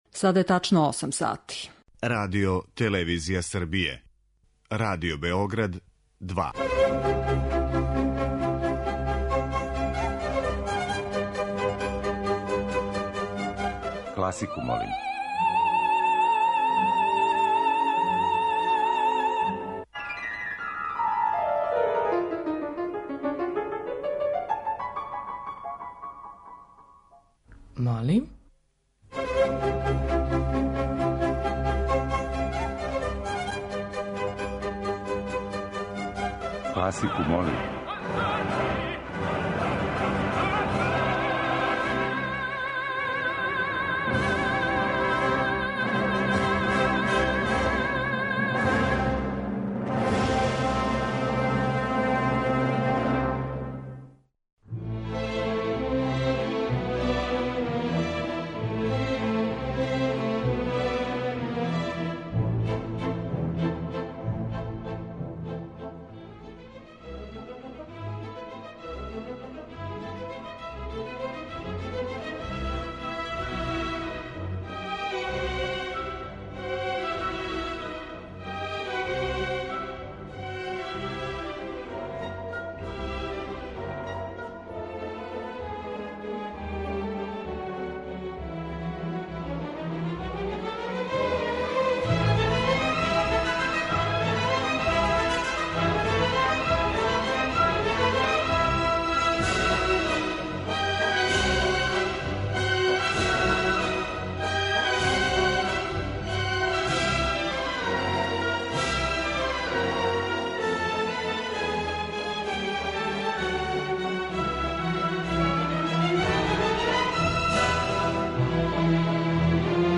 Оперске улоге лирских сопрана